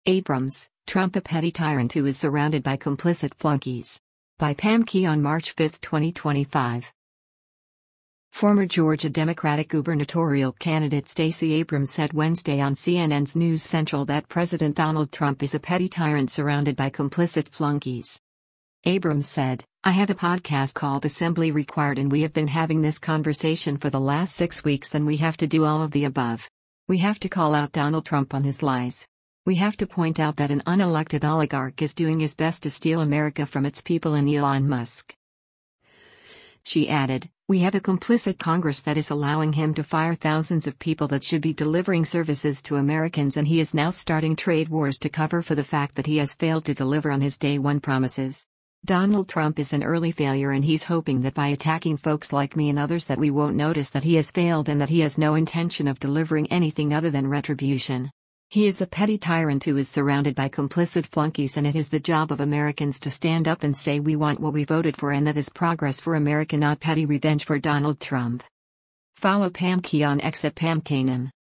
Former Georgia Democratic gubernatorial candidate Stacey Abrams said Wednesday on CNN’s “News Central” that President Donald Trump is a “petty tyrant’ surrounded by “complicit flunkies.”